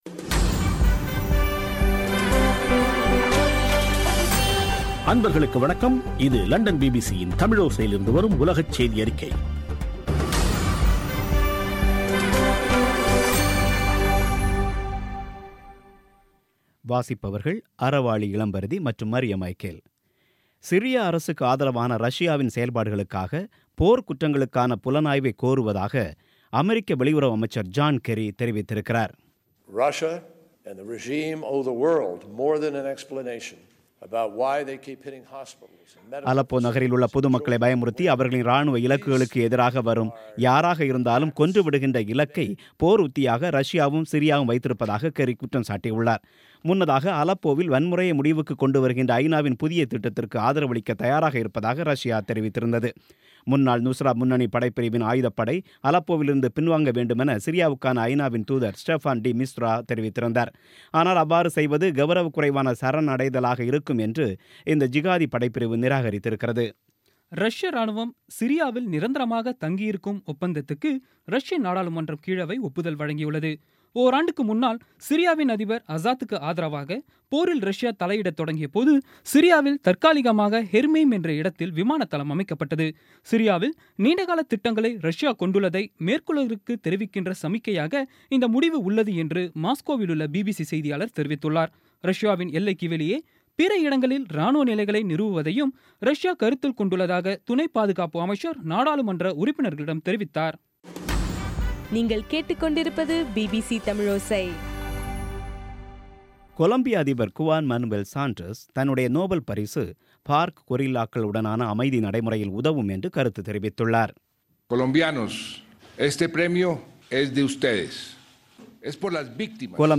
இன்றைய (அக்டோபர் 7ம் தேதி ) பிபிசி தமிழோசை செய்தியறிக்கை